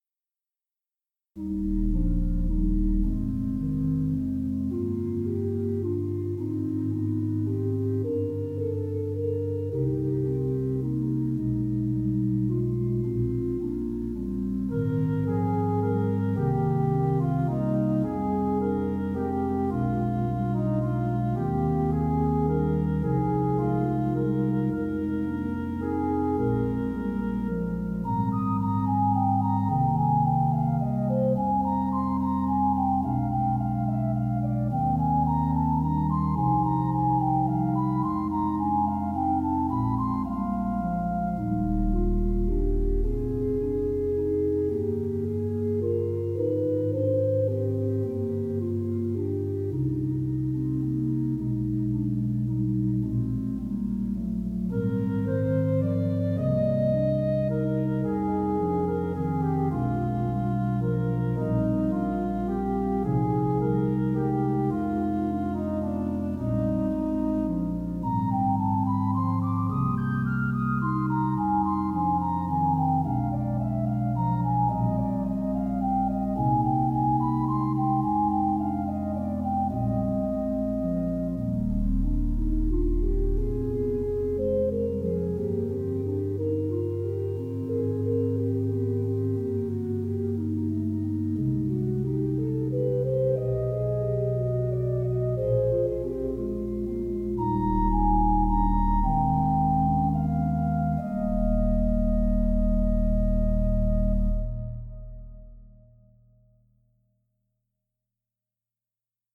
Préludes à 2claviers pedaliter